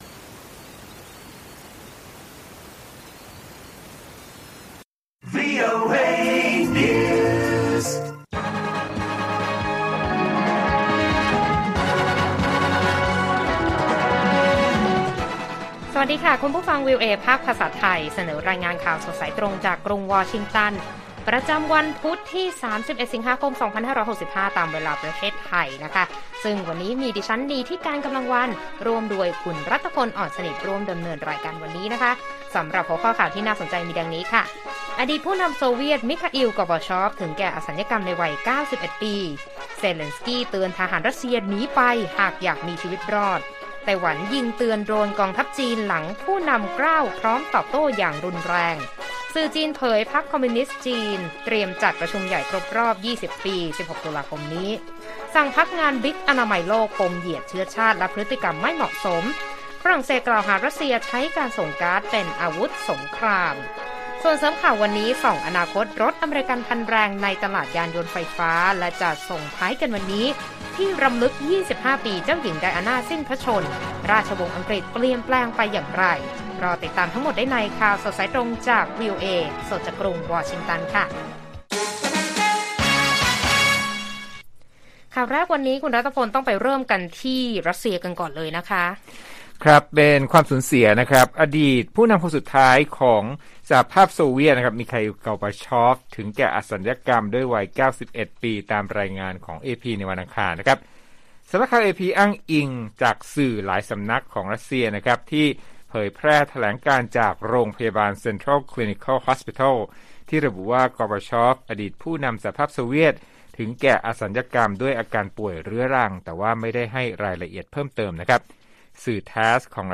ข่าวสดสายตรงจากวีโอเอ ไทย พุธ 31 สิงหาคม 2565